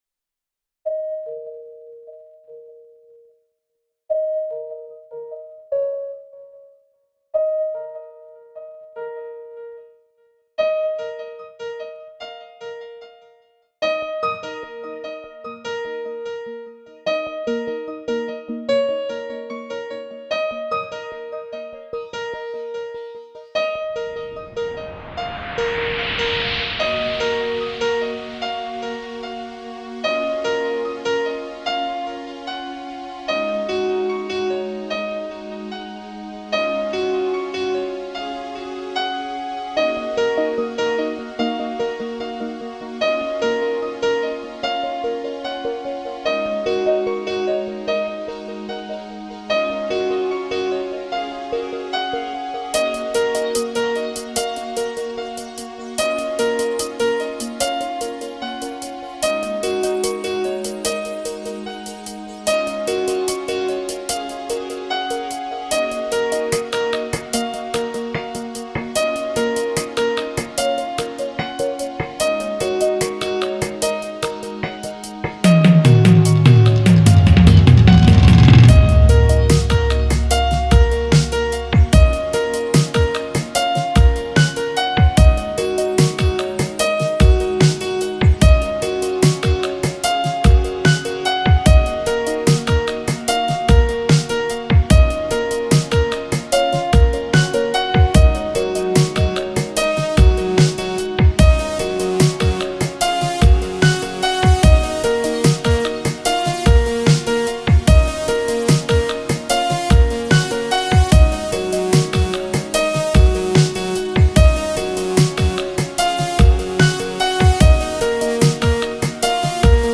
آهنگ ملوی "UM" ساخته خودم با اف ال استودیو
trance